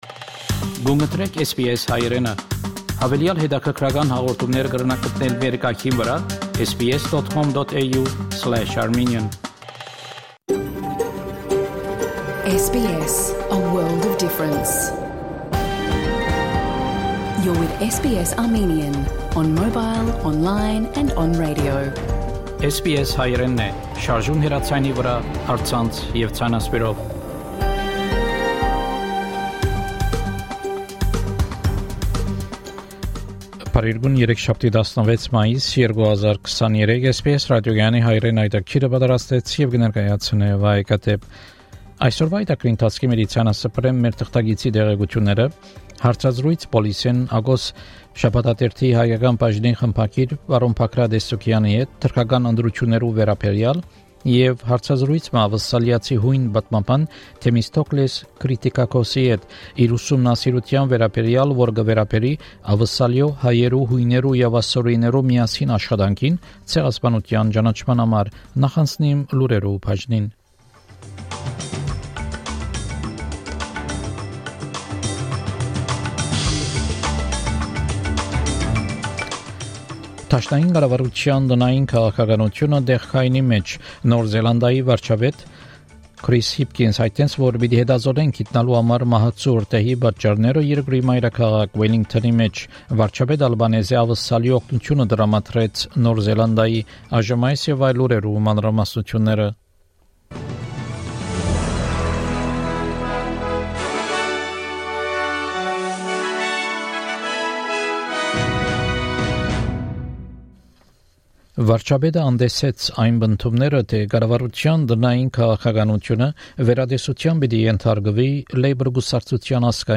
SBS Armenian news bulletin – 16 May 2023